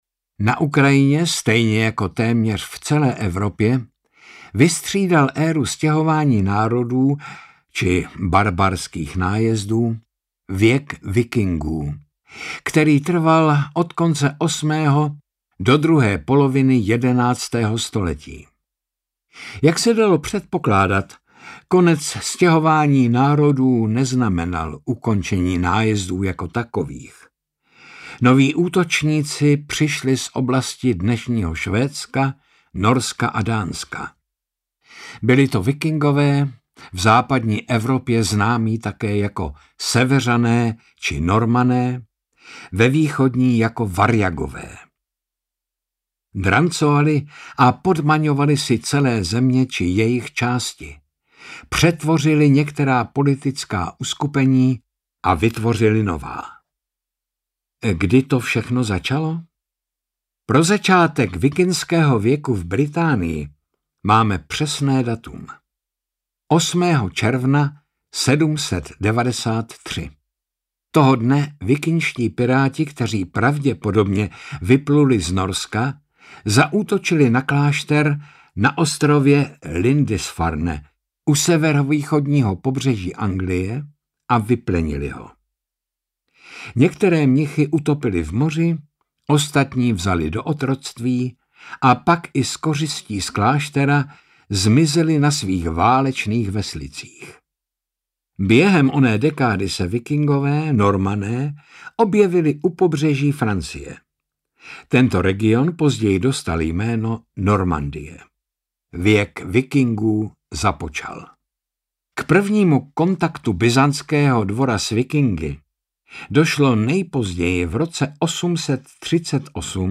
Audiobook
Read: Jaromír Meduna